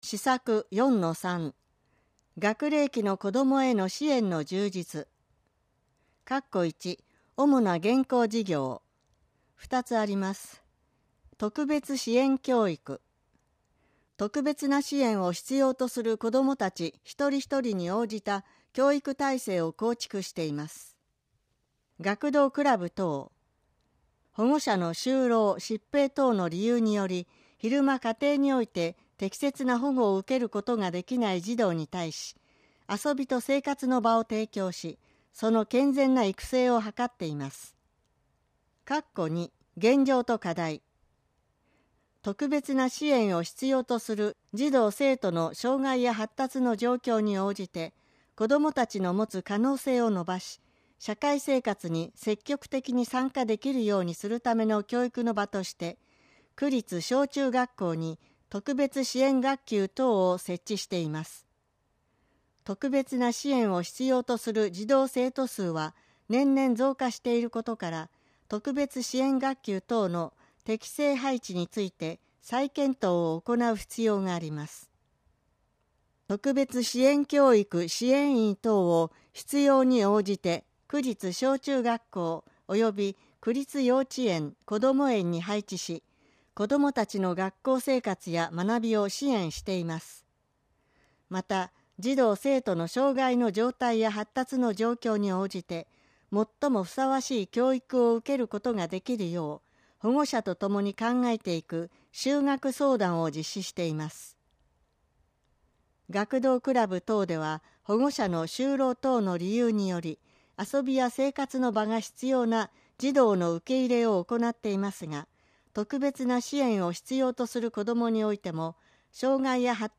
計画の音声版（デイジー版）CDを区役所の障害者福祉課及び区立図書館で貸出しています。